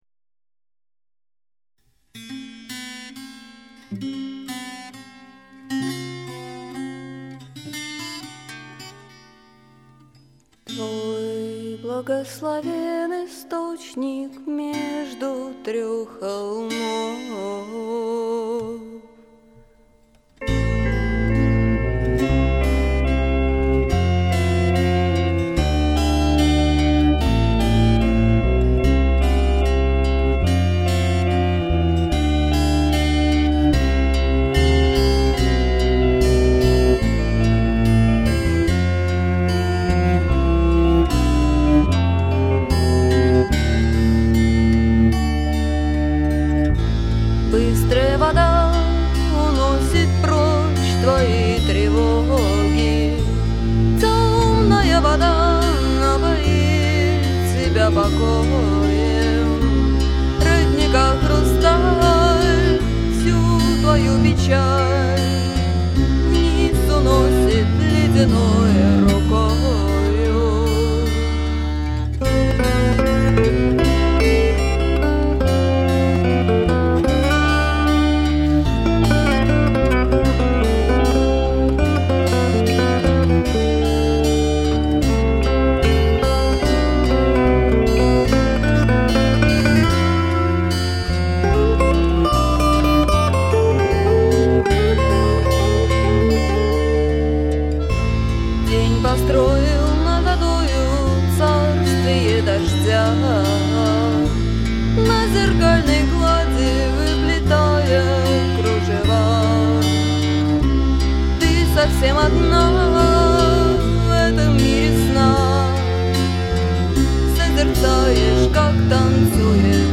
вокалы
электрогитара [5]